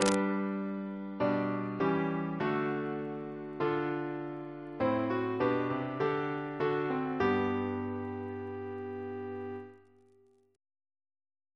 Single chant in G Composer: Christopher Gibbons (1615-1676) Reference psalters: ACB: 207; H1982: S15